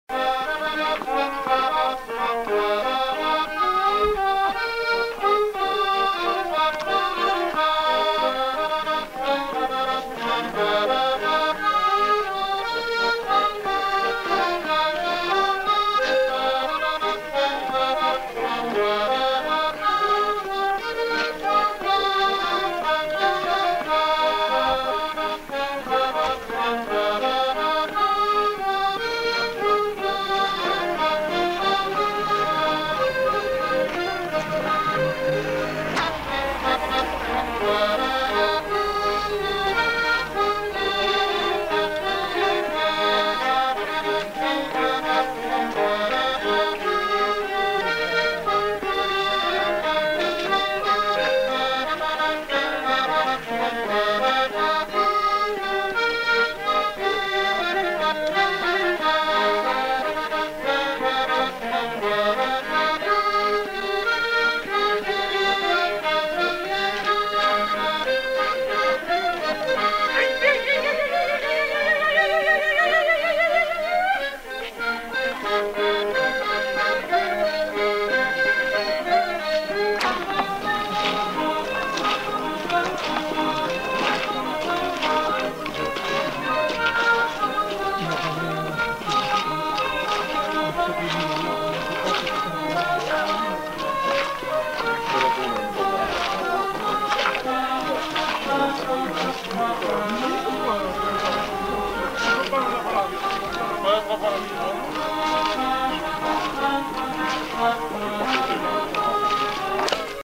Aire culturelle : Savès
Lieu : Espaon
Genre : morceau instrumental
Instrument de musique : accordéon diatonique
Danse : valse